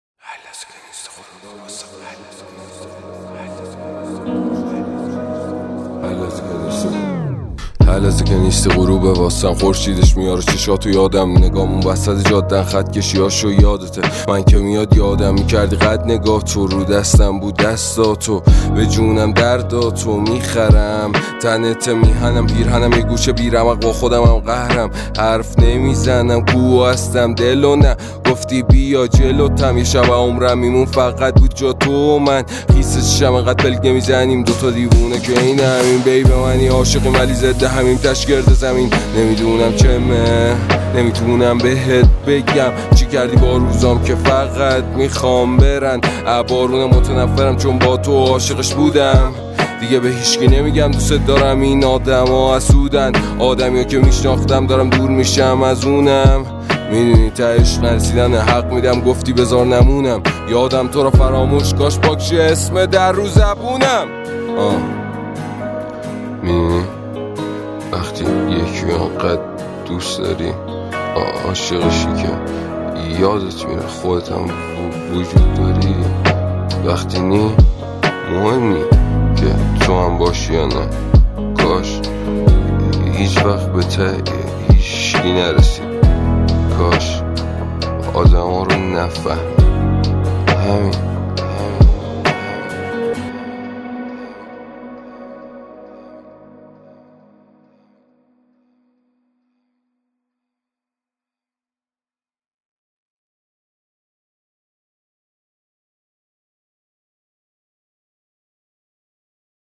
ترانه محلی جدید